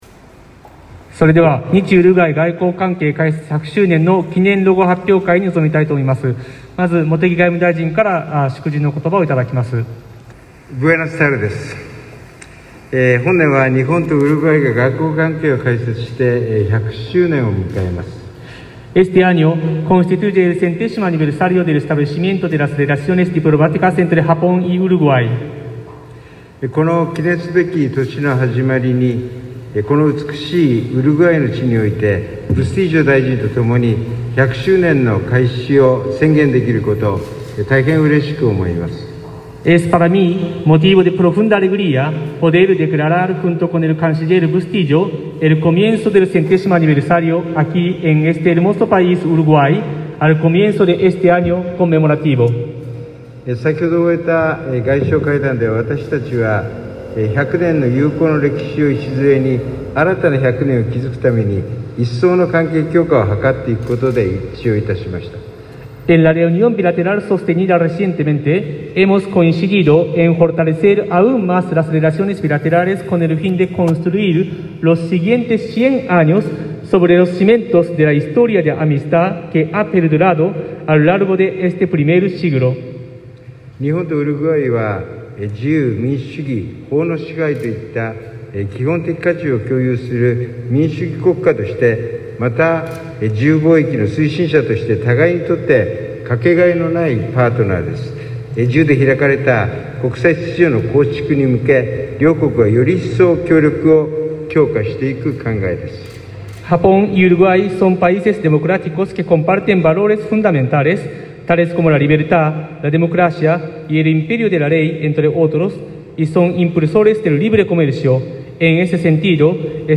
Palabras del canciller japonés, Toshimitsu Motegi, tras su encuentro con su par uruguayo Francisco Bustillo
Declaraciones del canciller japonés, Toshimitsu Motegi, en el marco de su visita oficial a Uruguay, que incluyó la colocación de una ofrenda floral al pie del monumento al Gral. José Artigas; un encuentro de trabajo con el canciller uruguayo, Francisco Bustillo y la posterior firma de acuerdos, y una reunión con el presidente de la República, Luis Lacalle Pou, finalizando su gira.